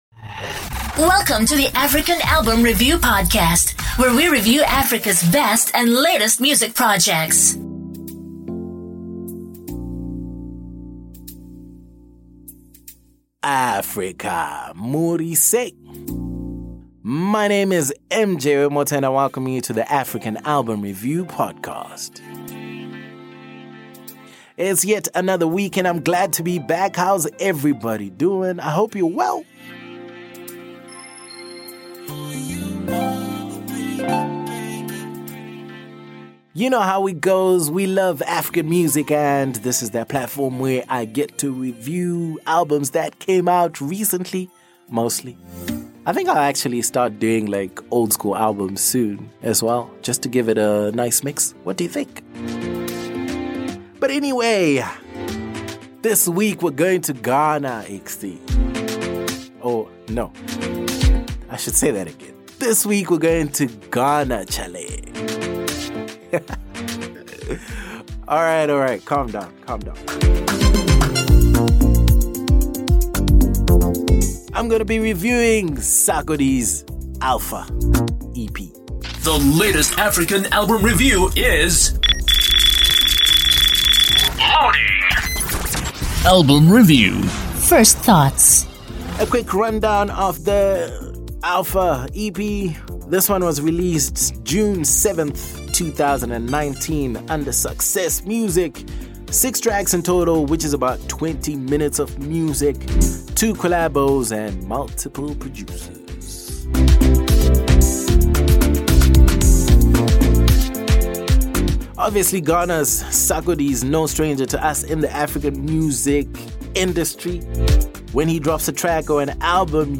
Sarkodie: Alpha EP REVIEW Ghana